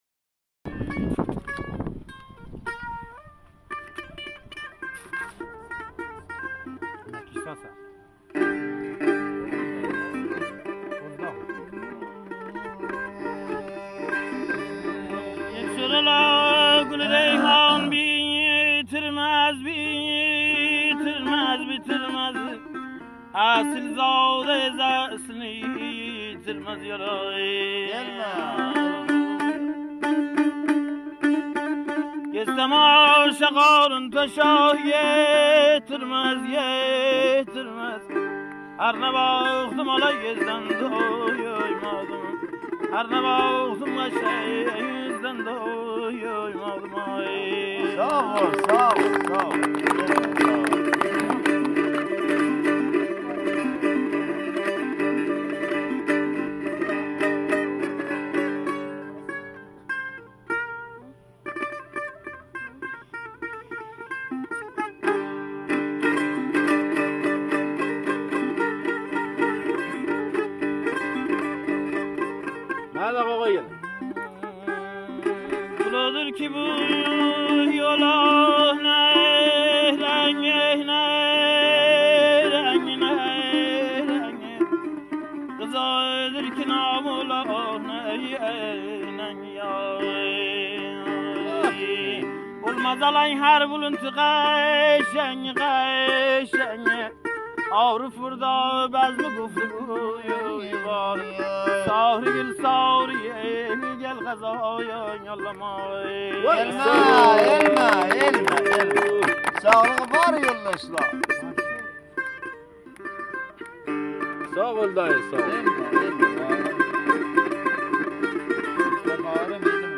ترانه: فولک، موزیک و تنظیم